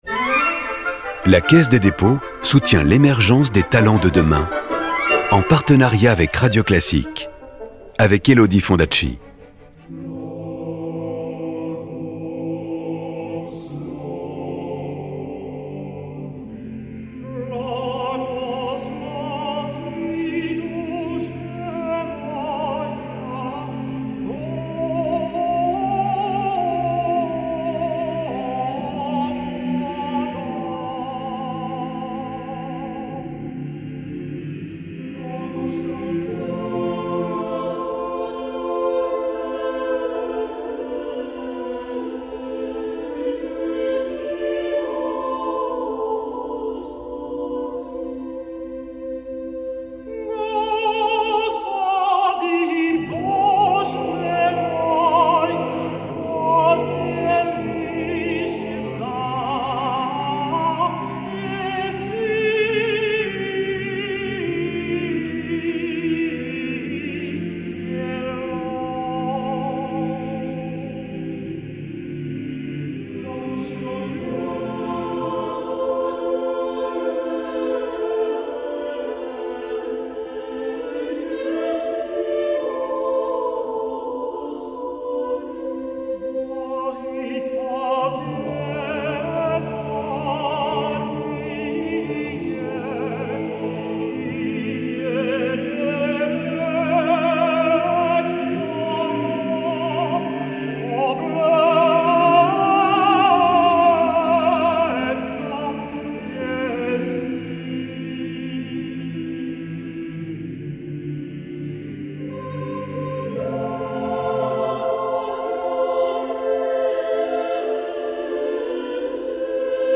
La Compagnie vocale et instrumentale